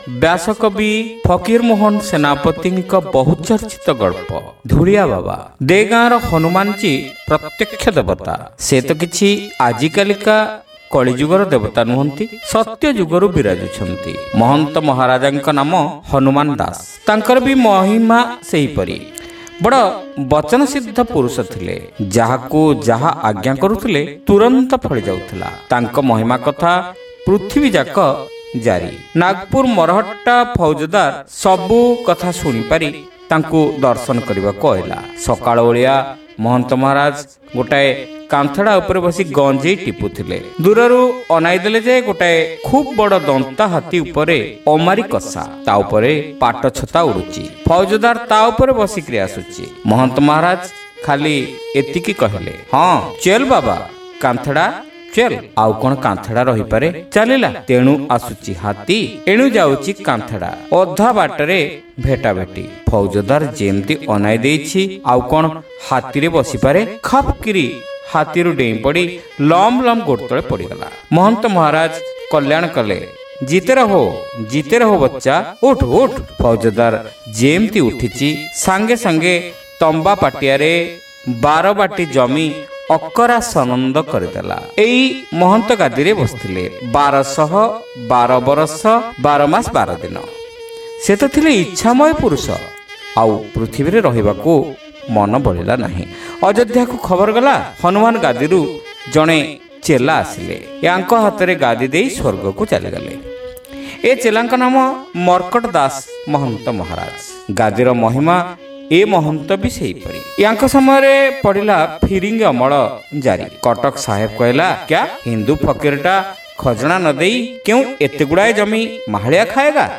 ଶ୍ରାବ୍ୟ ଗଳ୍ପ : ଧୂଳିଆବାବା (ପ୍ରଥମ ଭାଗ)